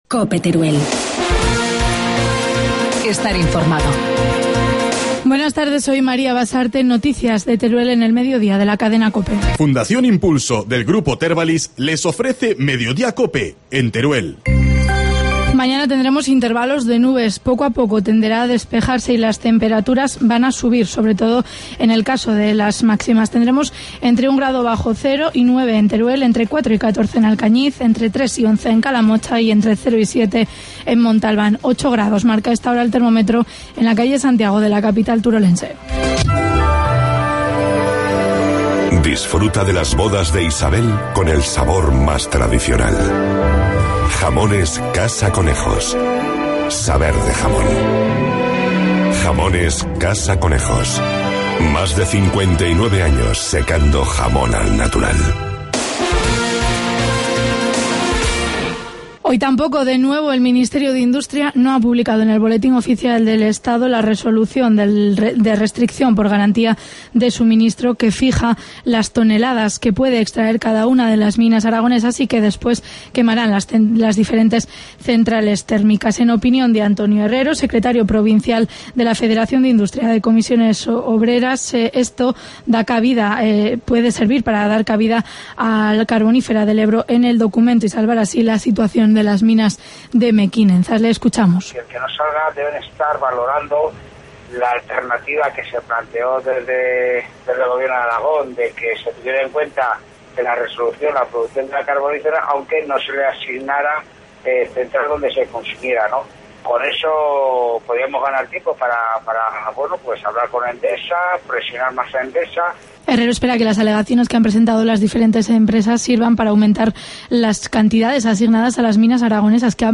Informativo mediodía, miércoles 13 de febrero